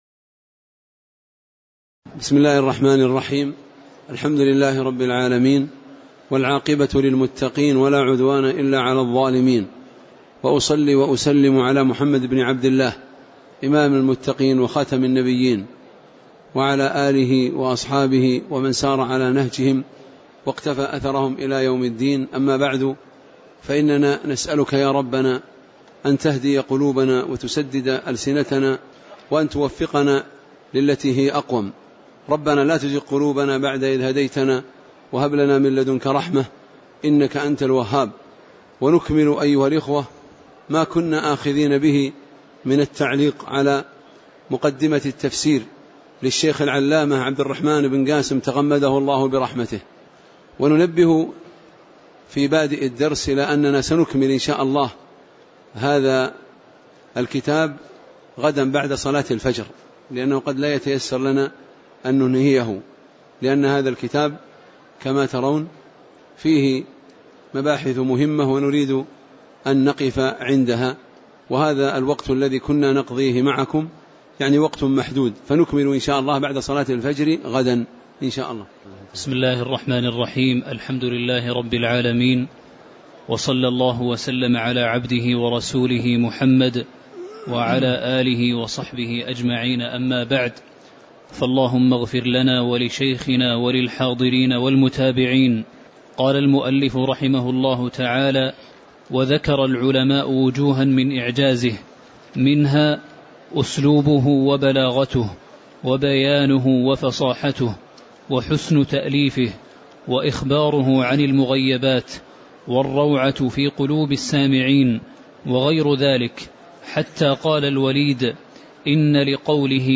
تاريخ النشر ١٤ شوال ١٤٣٩ هـ المكان: المسجد النبوي الشيخ